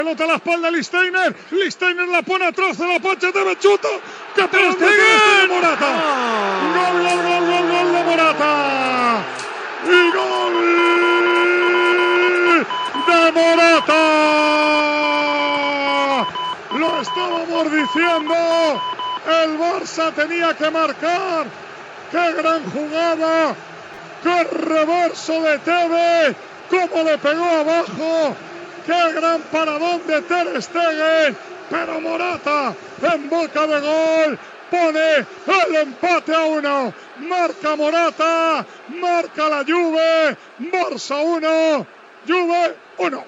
Transmissió de la final de la Copa d'Europa de futbol masculí, des de l'Olympiastadion de Berlín, del partit entre el Futbol Club Barcelona i la Juventus.
Naracció del gol d'Álvaro Morata per a la Juventus
Esportiu